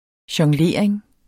Udtale [ ɕʌŋˈleˀɐ̯eŋ ]